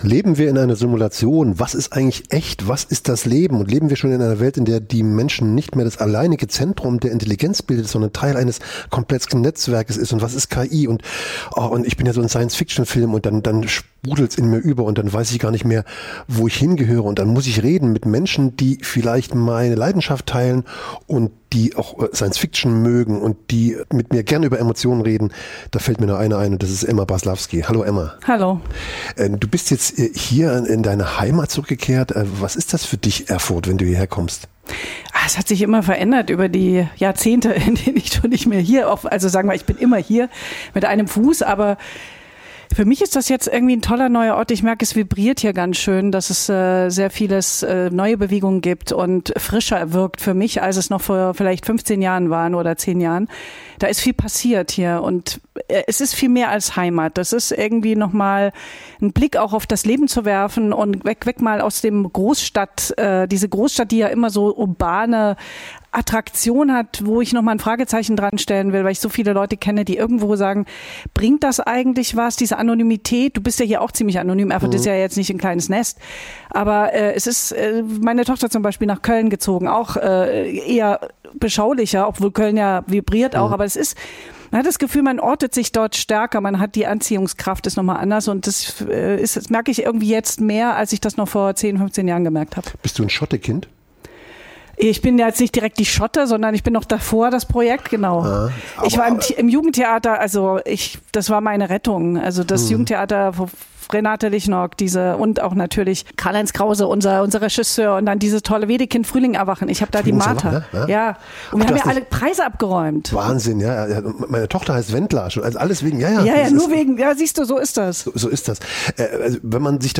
Ein Gespräch über Zukunft, Macht � und darüber, warum Intelligenz mehr ist als Rechenleistung.